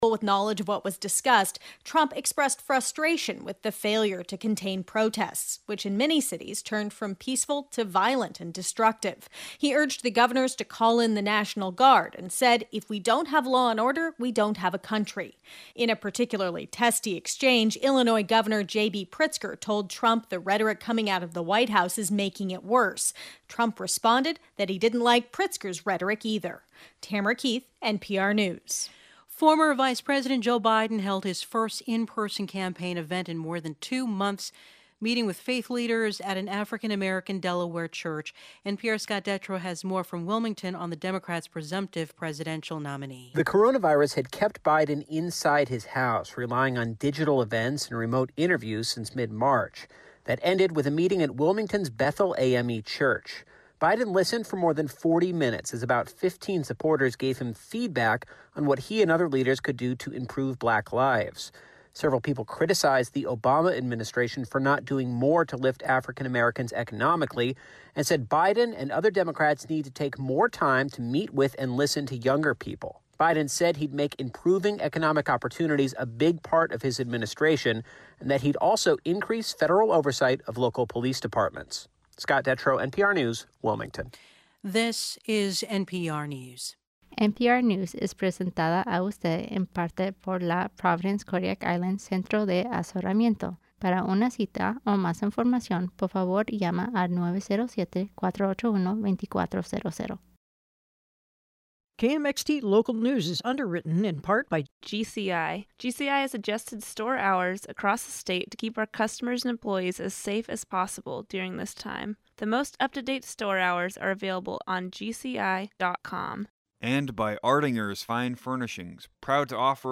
Newscast — Monday June 1, 2020